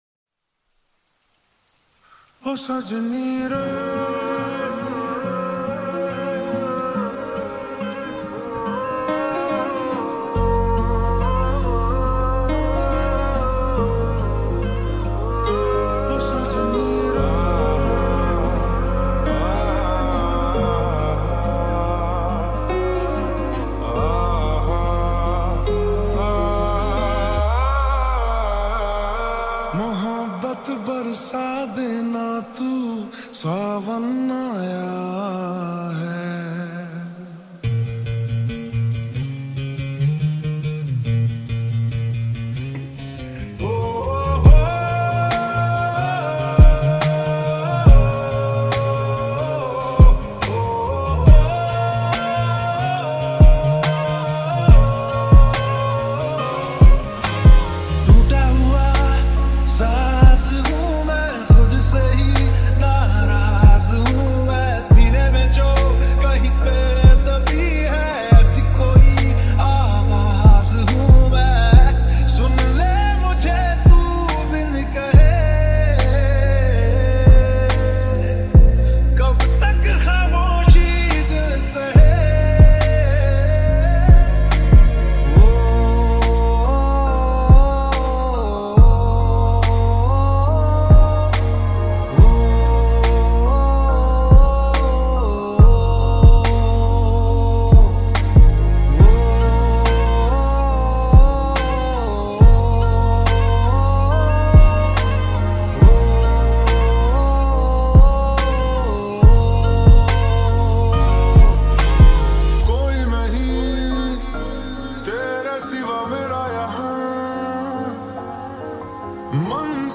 Lo-Fi Chillout Mashup